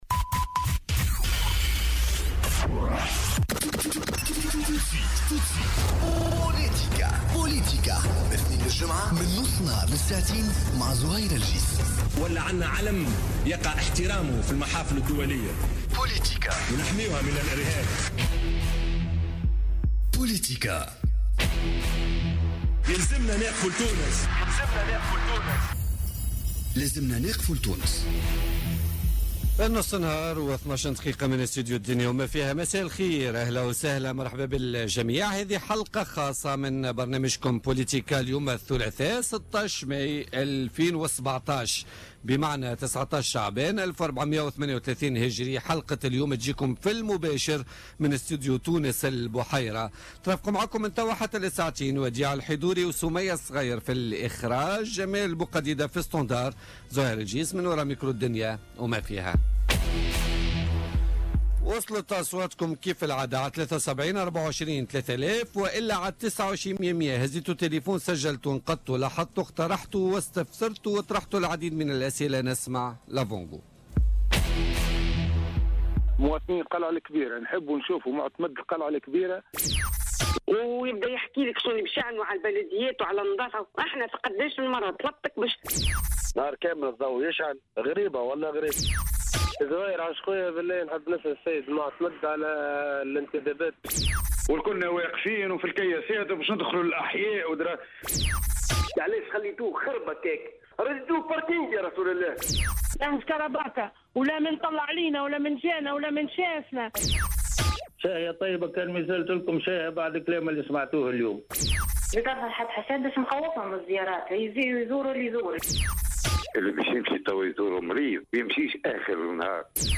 قال الكاتب الصحفي الصافي سعيد ضيف بولتيكا اليوم الثلاثاء 16 ماي 2017 إن هذه الحكومة فاشلة وغي قادرة على التحكم في المشاكل التي تواجه تونس وهي مطالبة اليوم بالتنحي.